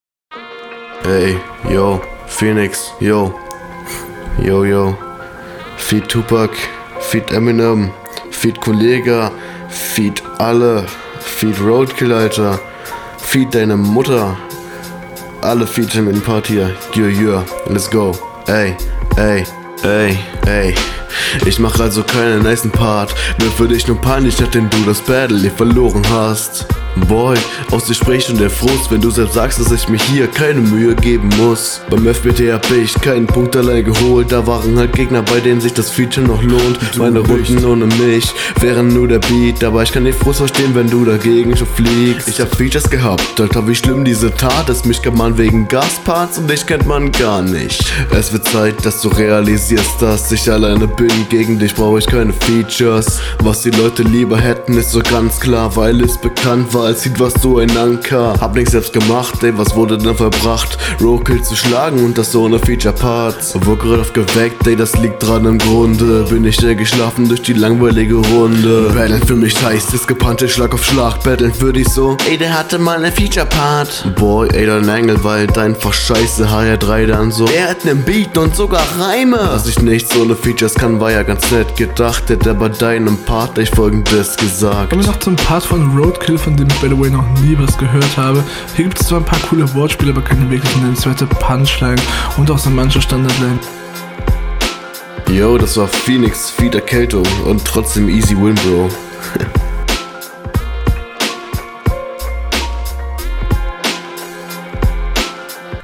Flowlich nehmt ihr euch da beide nicht viel, es klingt in Ordnung aber ist beides …
Stimme much too loud, aber der anfang war sehr witzig. Flow der stockend und noch …